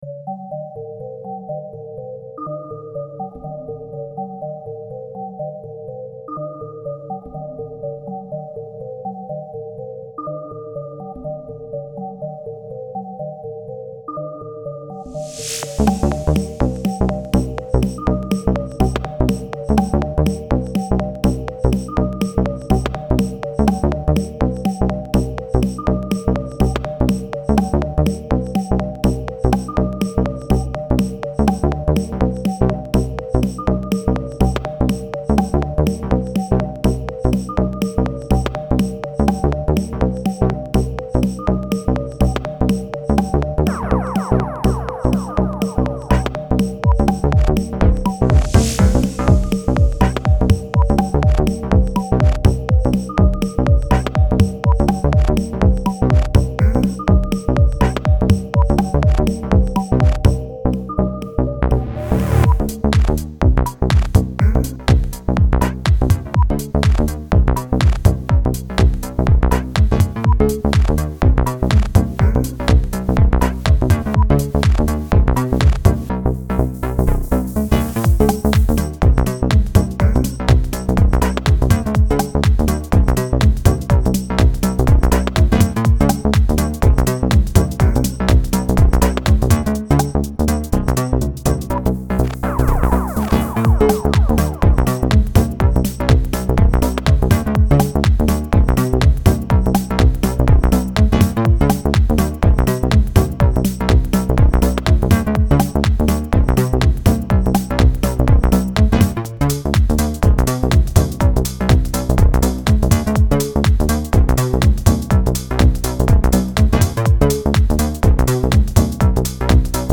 сэт в 10 минут
отыгрался на еже в нем же выращенными сэмплами